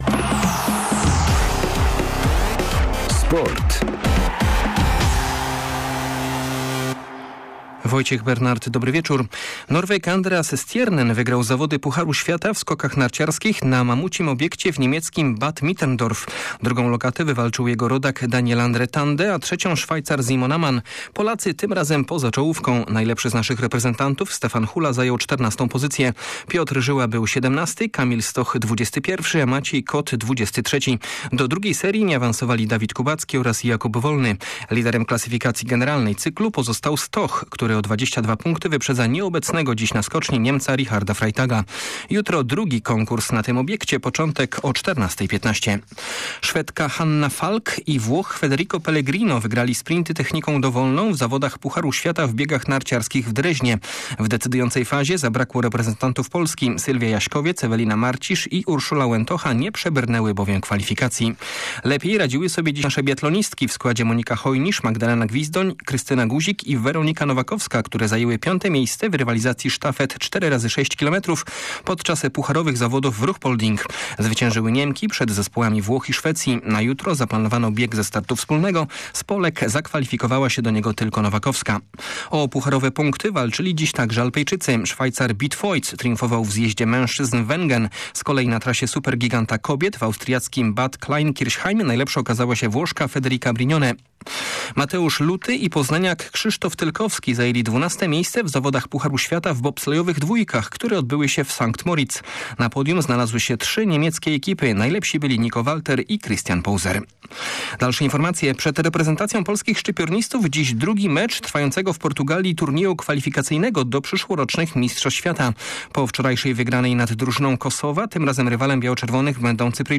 13.01 serwis sportowy godz. 19:05